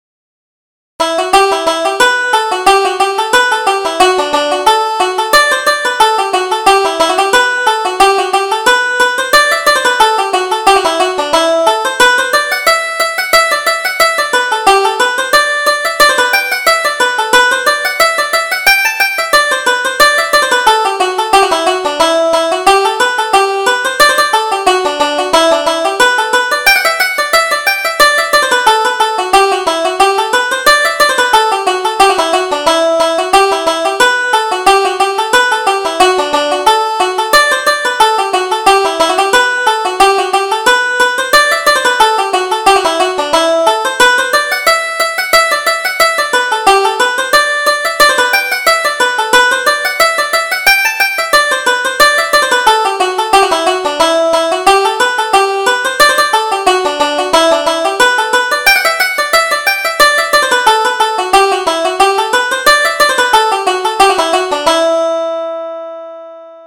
Reel: Mayor Harrison's Fedora